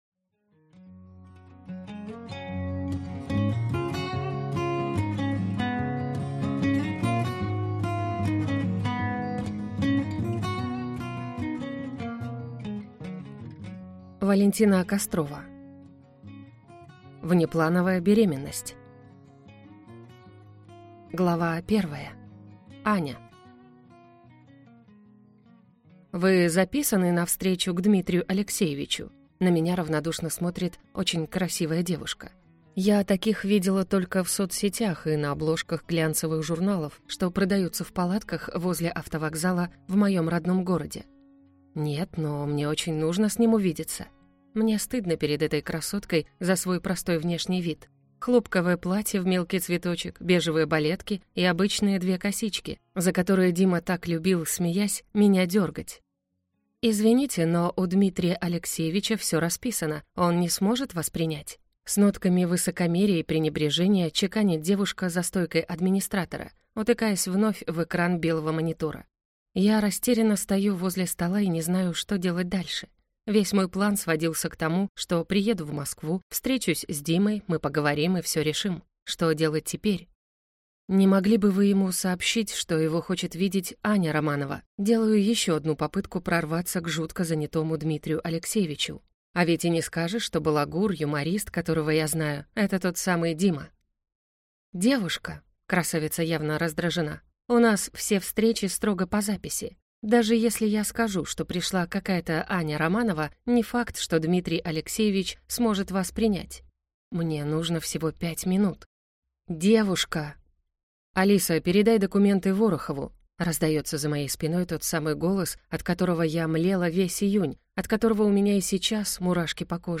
Аудиокнига Внеплановая беременность | Библиотека аудиокниг